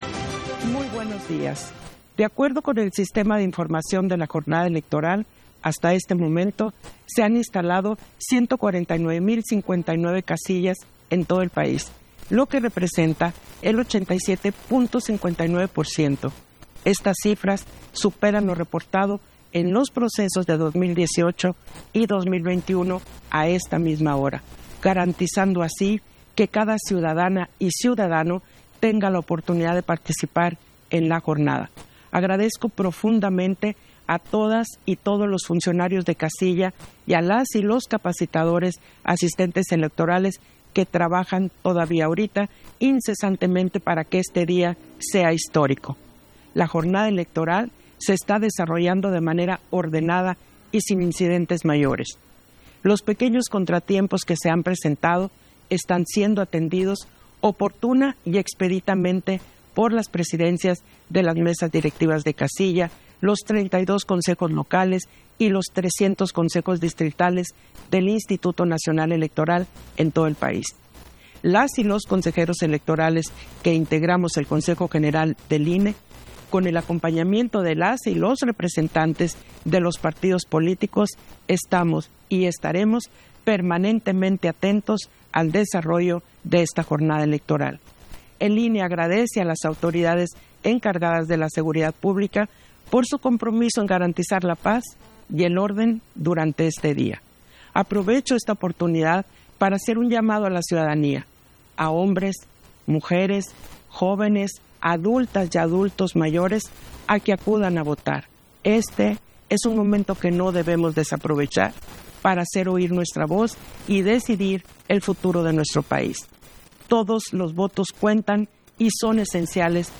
Primer mensaje en cadena nacional de la Consejera Presidenta, Guadalupe Taddei, con motivo de la jornada electoral 2024